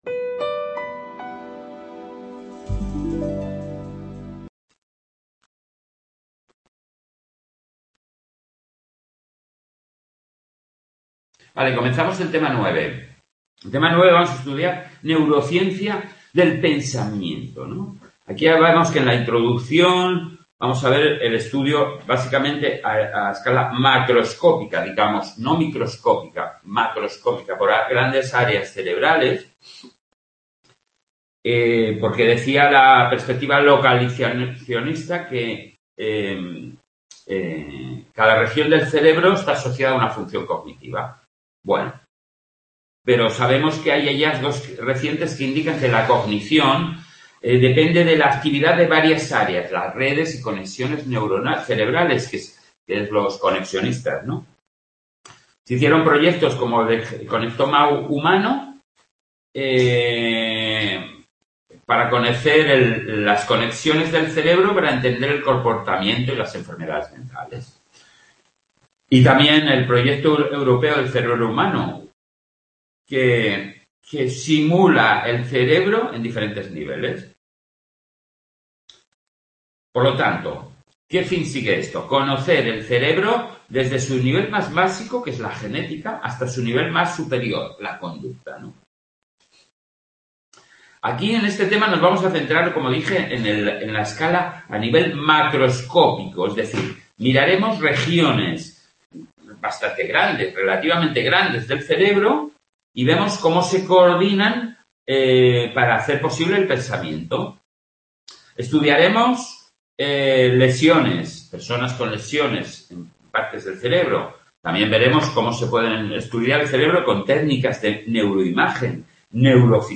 en el C.A. de Sant Boi